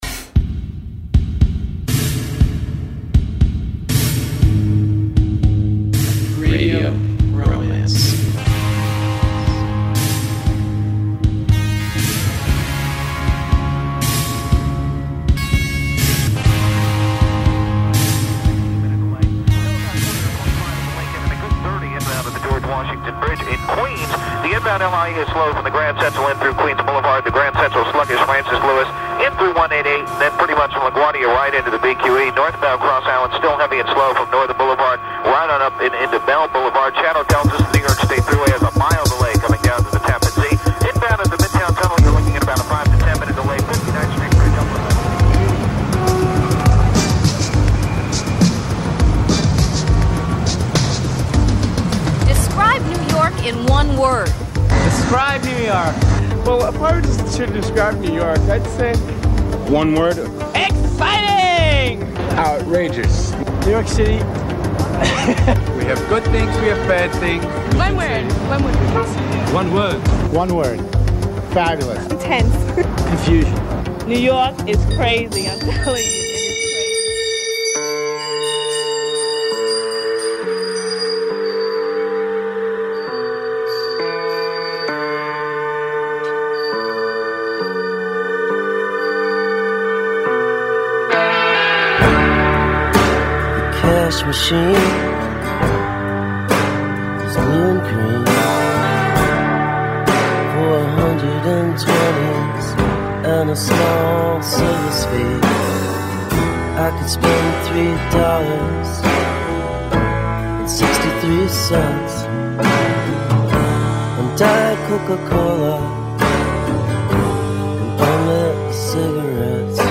Canzoni d'amore, di desiderio, di malinconia, di emozioni, di batticuore.